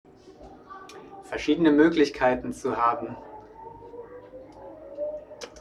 Standort der Erzählbox:
MS Wissenschaft @ Diverse Häfen
Standort war das Wechselnde Häfen in Deutschland.